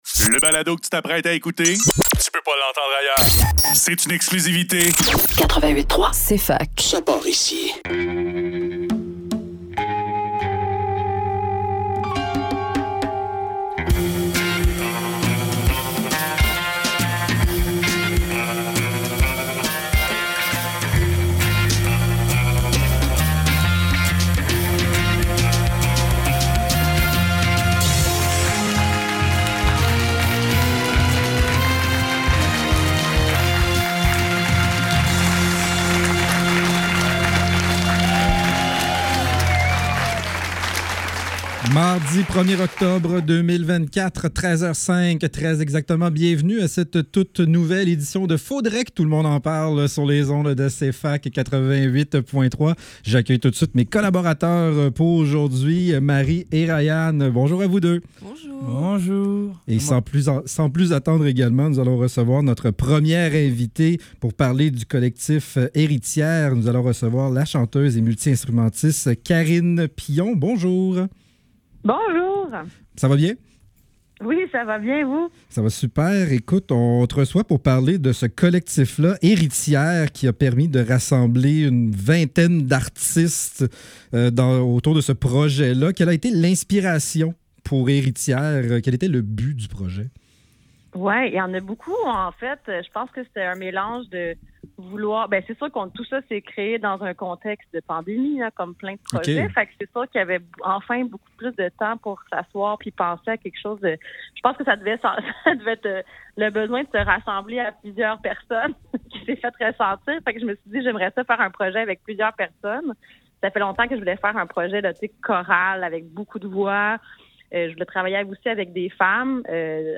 Faudrait que tout l'monde en parle - Entrevue avec Héritières